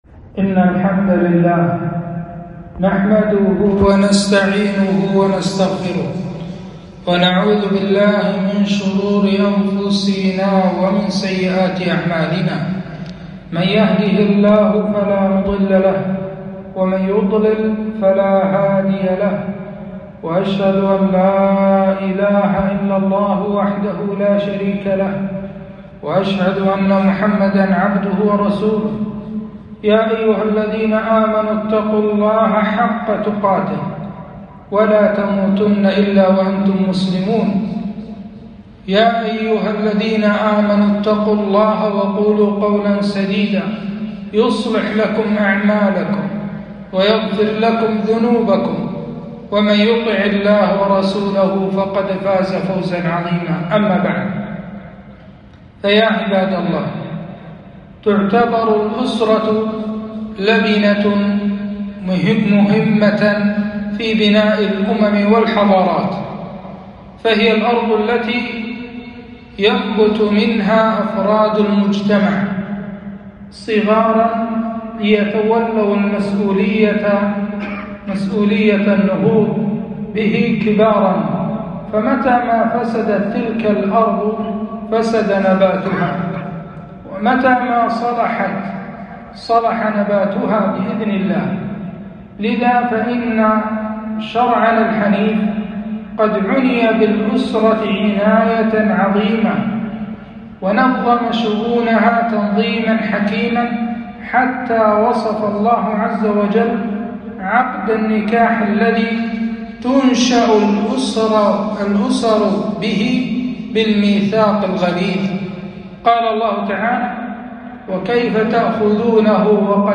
خطبة - التحذير من التساهل في الطلاق  - دروس الكويت